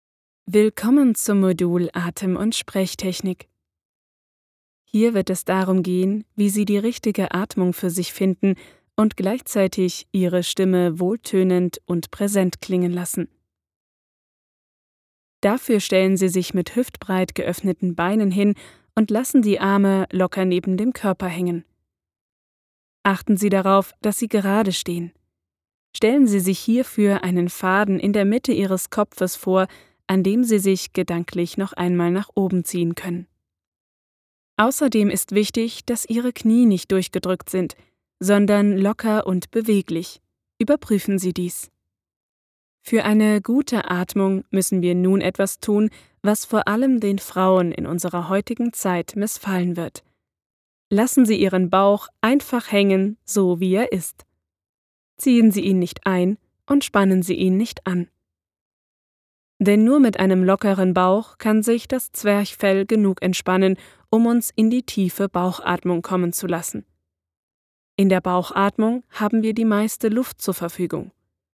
Eigenes prof. Studio vorhanden (Neumann TLM 103)
Sprechprobe: eLearning (Muttersprache):
E-Learning kurz.mp3